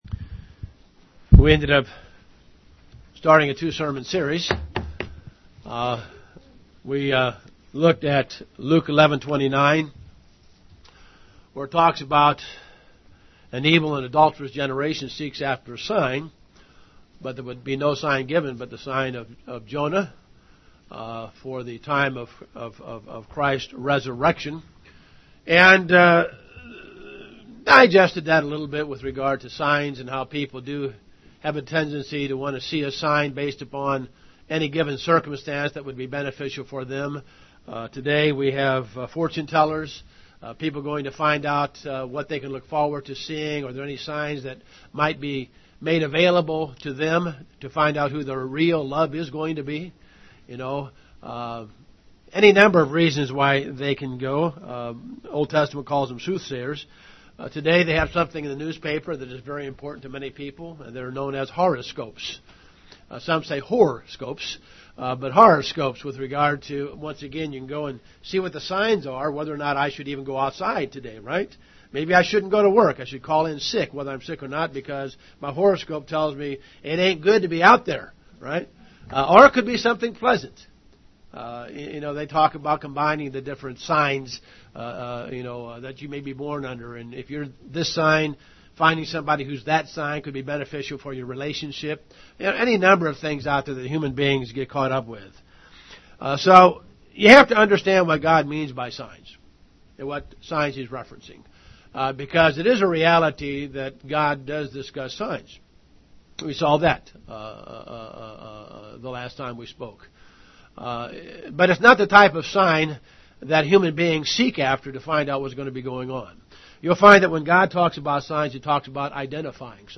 Given in Cincinnati North, OH
Print Signs For the Church - Three Signs In The Bible UCG Sermon Studying the bible?